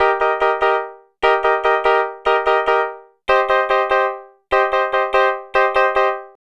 Twisting 2Nite 4 Piano-D#.wav